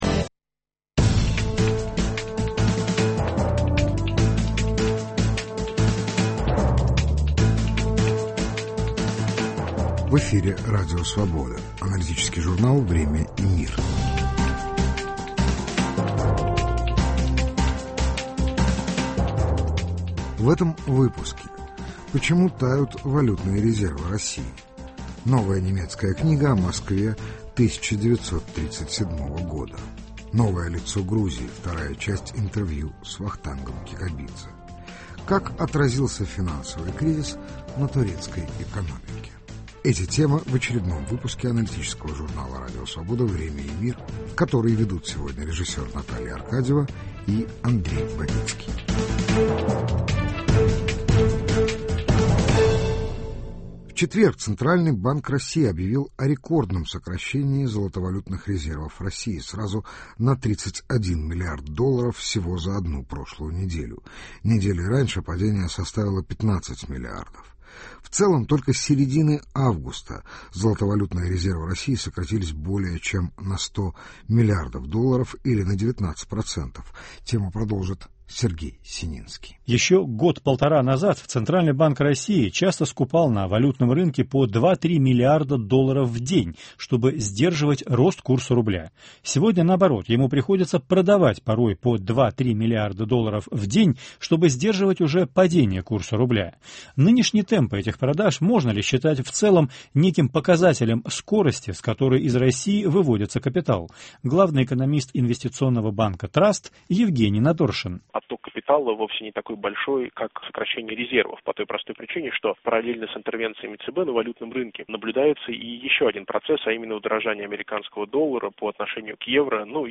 Новое лицо Грузии: 2 часть интервью с Вахтангом Кикабидзе.